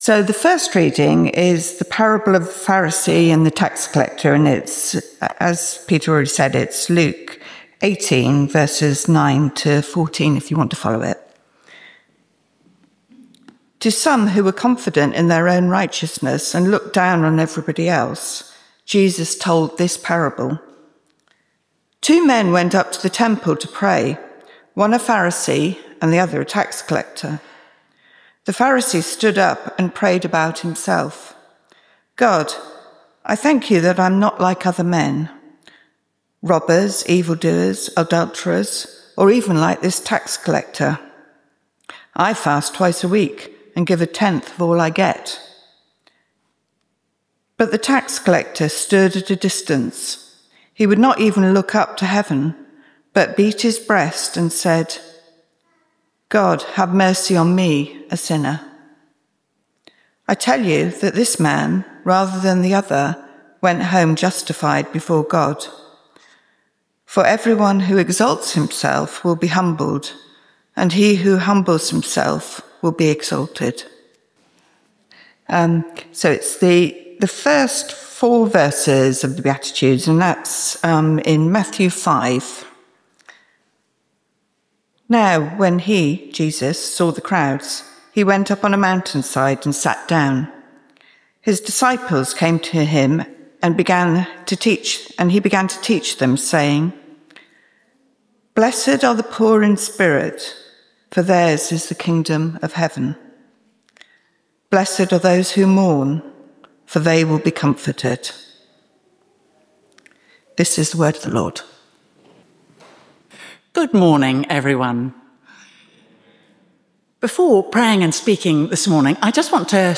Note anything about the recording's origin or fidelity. Location: St Mary’s, Slaugham Date Service Type: Communion